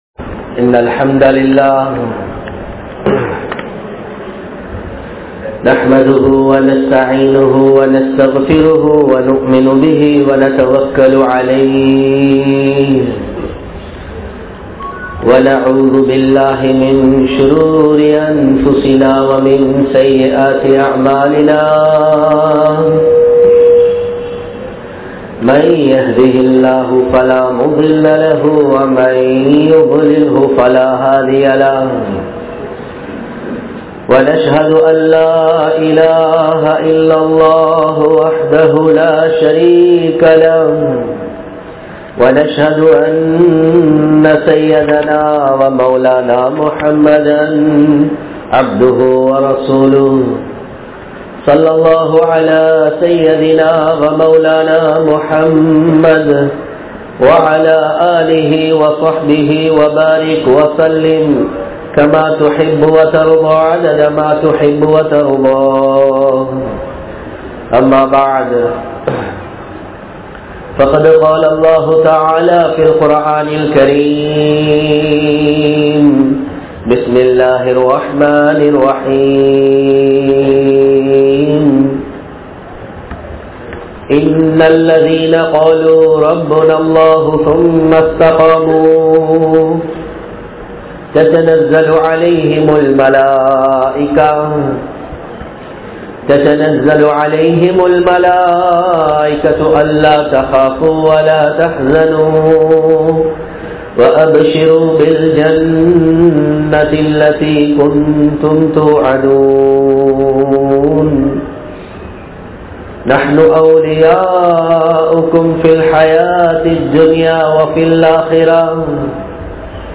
Thirumanathitku Mun Therinthu Kolla Veandiyavaihal (திருமணத்திற்கு முன் தெரிந்து கொள்ள வேண்டியவைகள்) | Audio Bayans | All Ceylon Muslim Youth Community | Addalaichenai